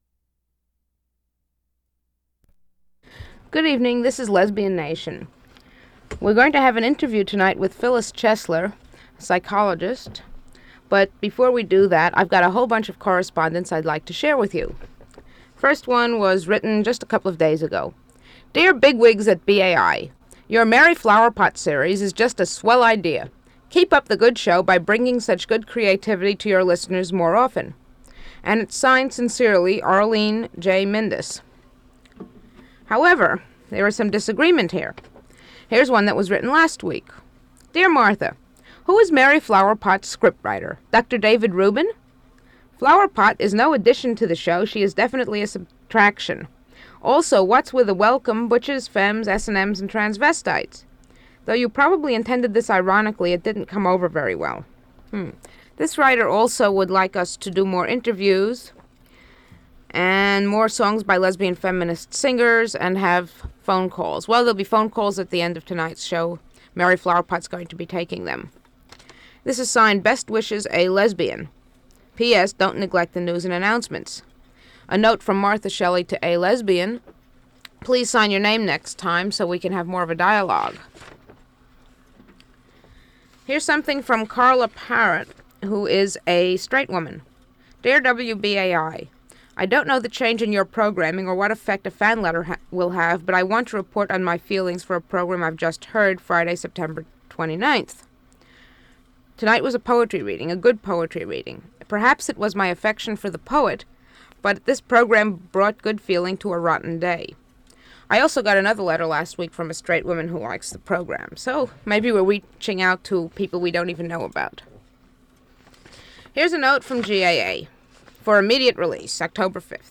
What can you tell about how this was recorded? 1/4 inch audio tape